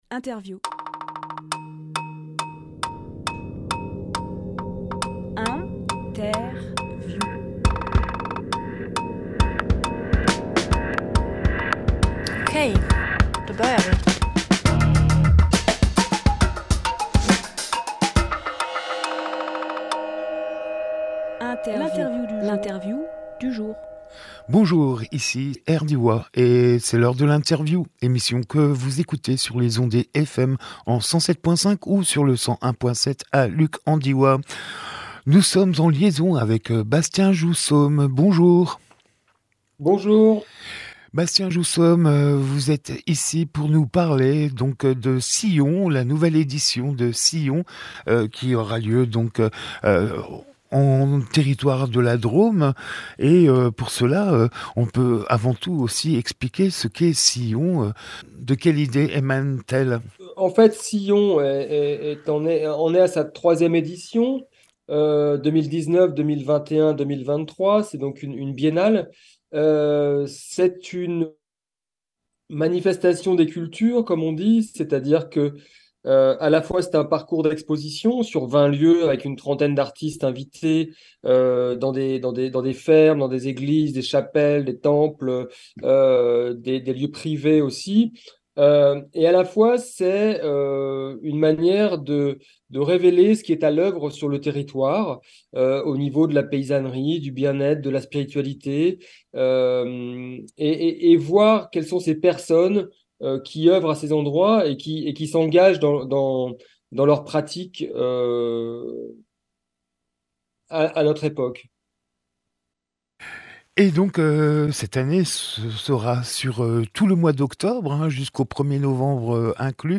Emission - Interview
05.10.23 Lieu : Studio RDWA Durée